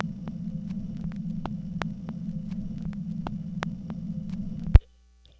Record Noises
Record_End_Long.aif